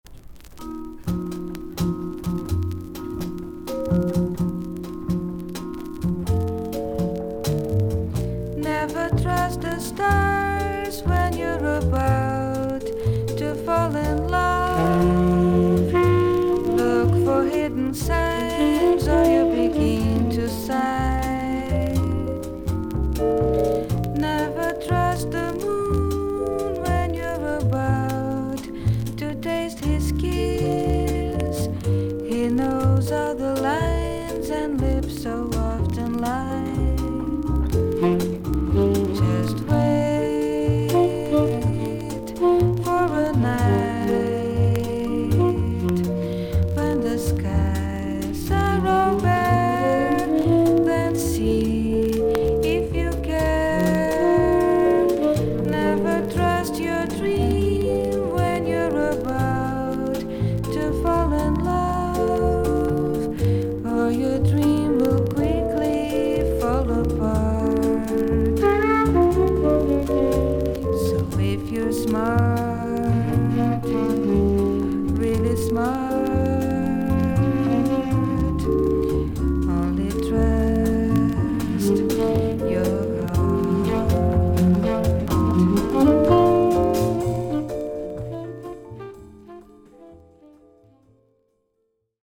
盤面に長短のキズがあり所々に周回ノイズあり。
全体的に少々サーフィス・ノイズあり。音自体はクリアです。
ジャズ・サックス奏者。
ニュー・ヨークのカフェ・オウ・ゴー・ゴーでのライヴを収録。